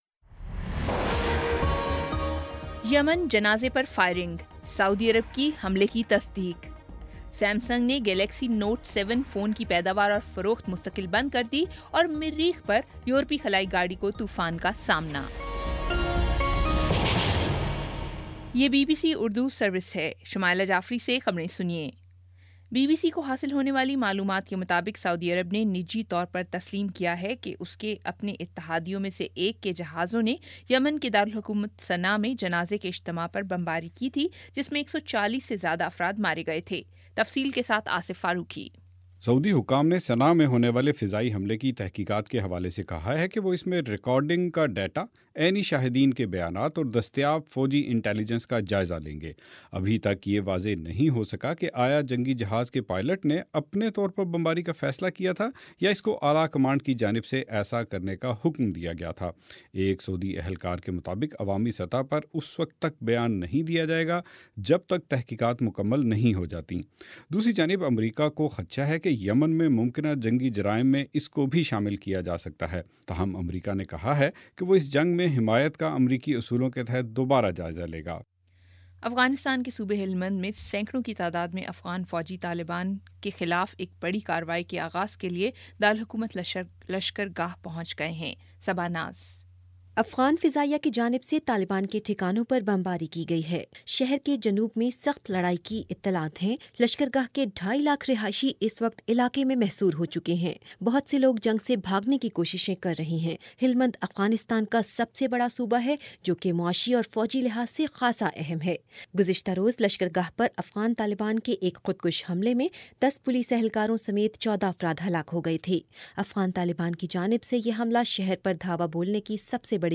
اکتوبر 11 : شام چھ بجے کا نیوز بُلیٹن